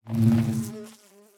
Minecraft Version Minecraft Version latest Latest Release | Latest Snapshot latest / assets / minecraft / sounds / block / beehive / work2.ogg Compare With Compare With Latest Release | Latest Snapshot